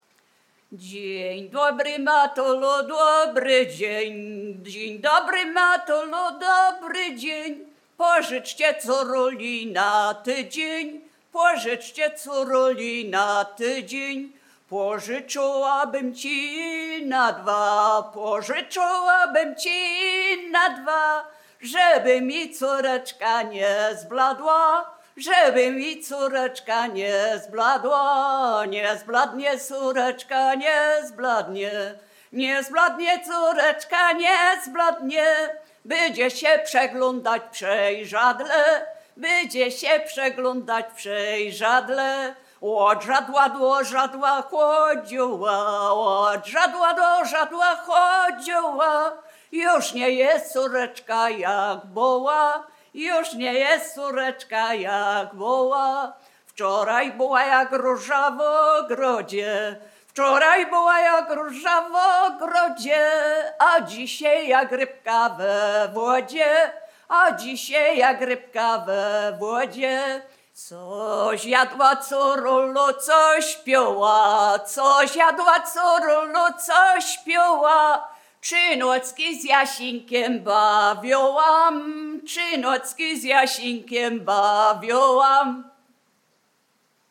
Obyczajowa
Array liryczne obyczajowe miłosne